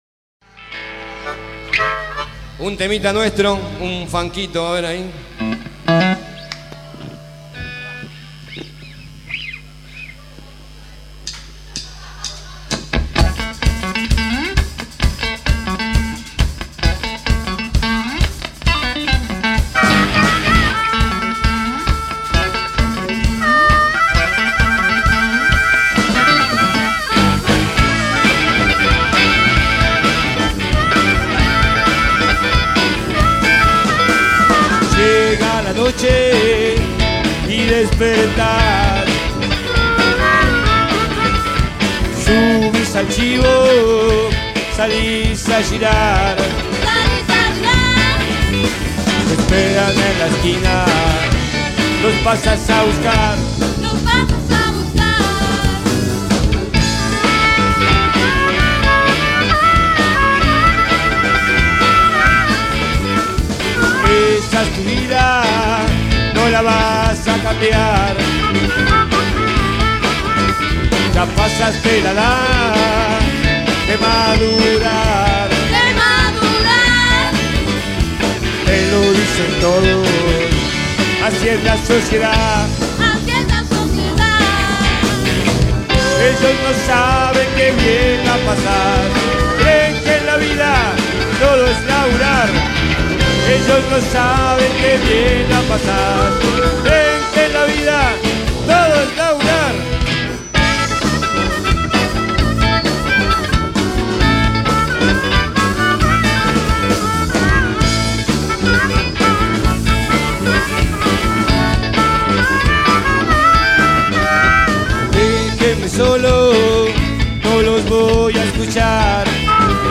Guitarra y Voz
Batería
Armónica
Teclados
Bajo y Voz